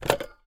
Здесь вы найдете характерные шумы вращающегося диска, гудки ожидания и другие аутентичные эффекты.
Звук поднятой трубки старого домашнего телефона